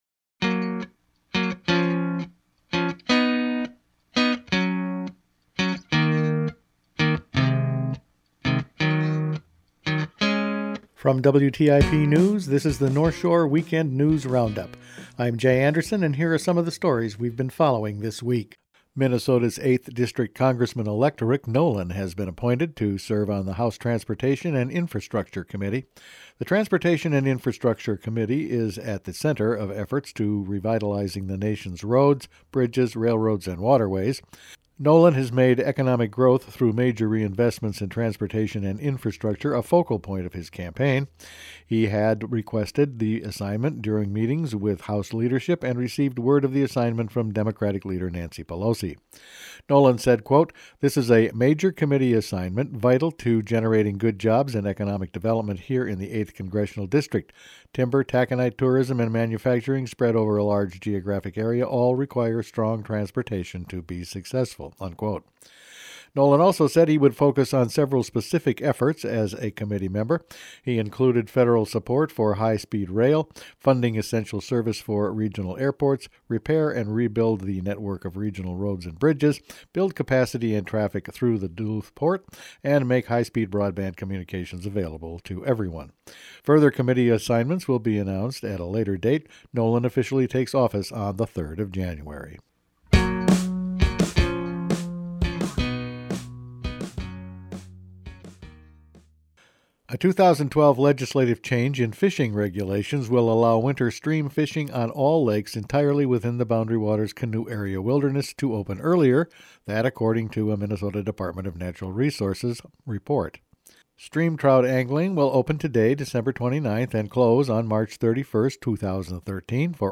Attachment Size WrapFinalCut_122812.mp3 20.02 MB Each weekend WTIP news produces a round up of the news stories they’ve been following this week. Important committee for Rick Nolan, changes in trout regs, Bakk call for education reform and Lonnie Dupre is back in Alaska…all in this week’s news.